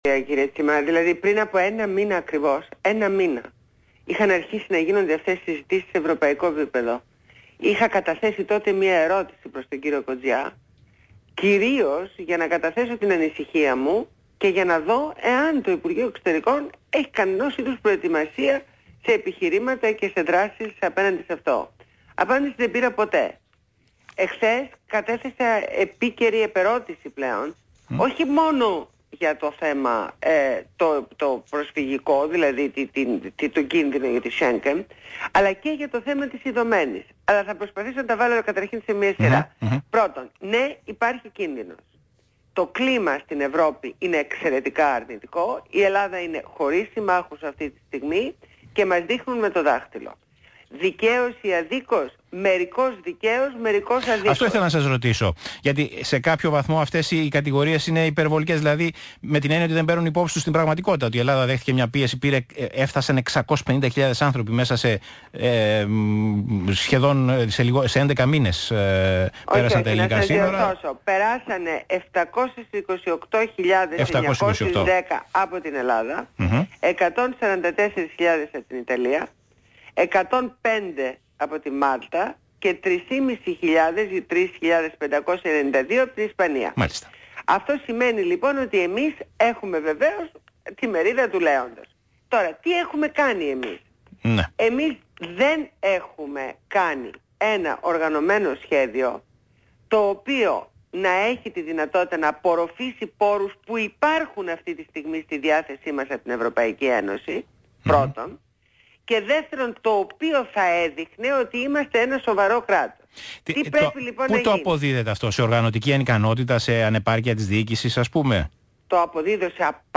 Συνέντευξη στο ραδιόφωνο του ΣΚΑΙ στο δημοσιογράφο Π. Τσίμα.